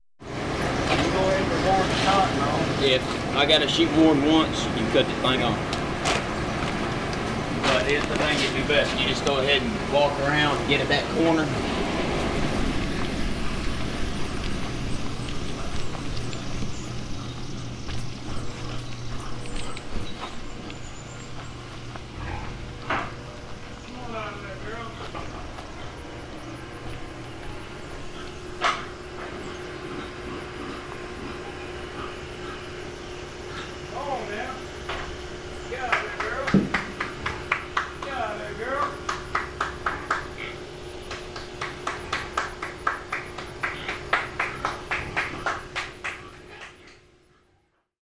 Hearing only the sound of the slaughter leaves the mind to fill in almost everything, biased with our own beliefs and preconceptions.
Field Recording Series by Gruenrekorder
But for the most part the focus seems to be on the absence of sounds, an aspect that chillingly emphasises death – the absence of life.